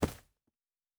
Footstep Carpet Running 1_02.wav